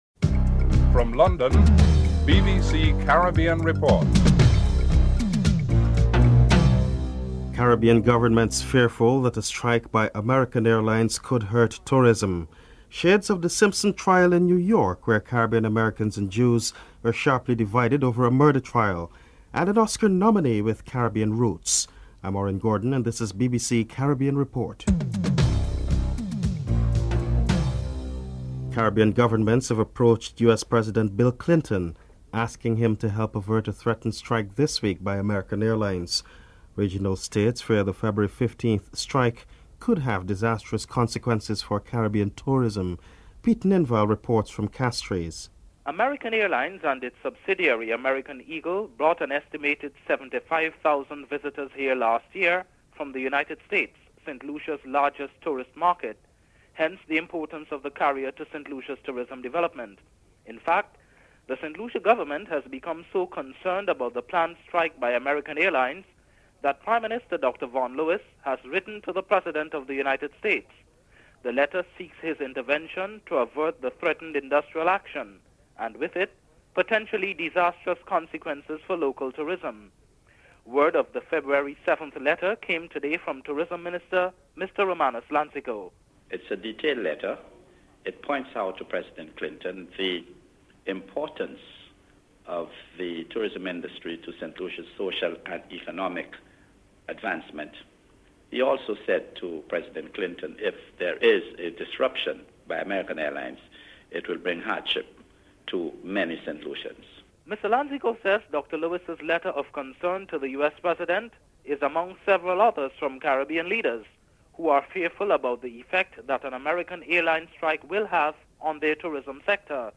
1. Headlines (00:00-00:28)
Sir James Mitchell, Prime Minster of St. Vincent and the Grenadines and Opposition Leader, Vincent Beache are interviewed (05:17-08:58)